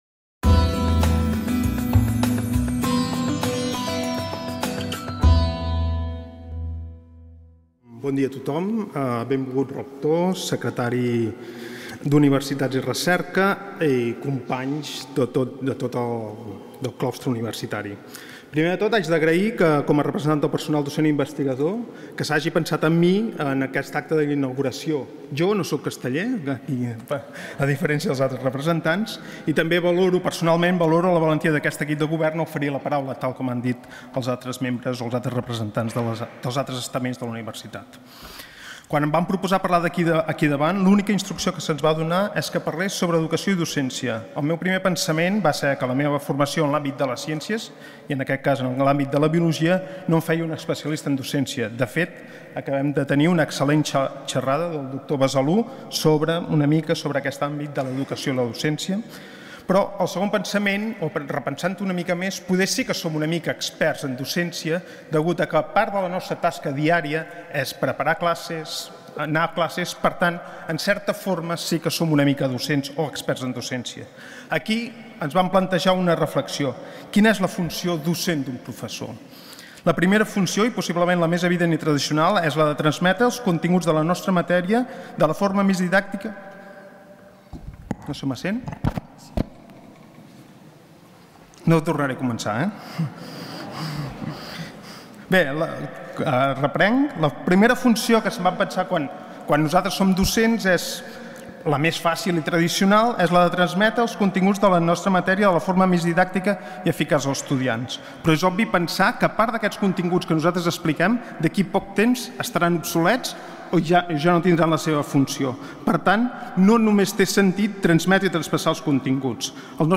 Inauguració del curs 2014-2015. Parlament